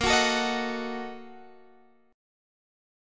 BbM7sus4#5 chord